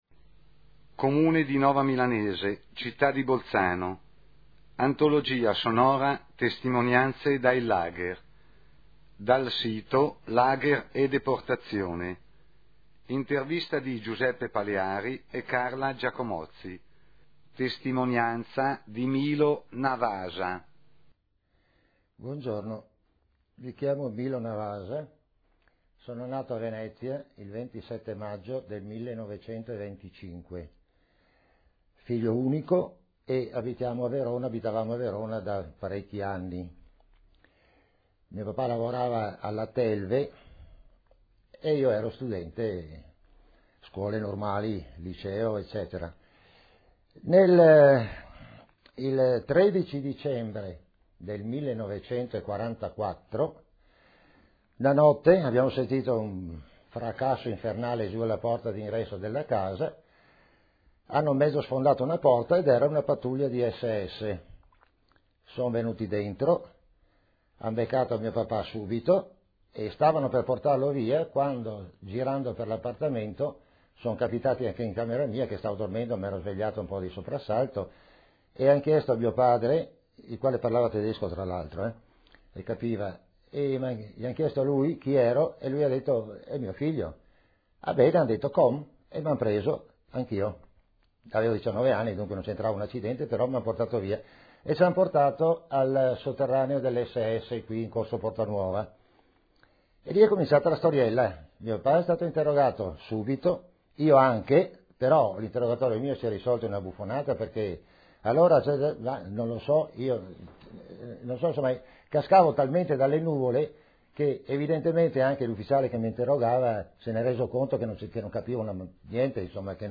Intervista del